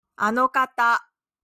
And the audio sounds like "alokatta" Or "arokatta"?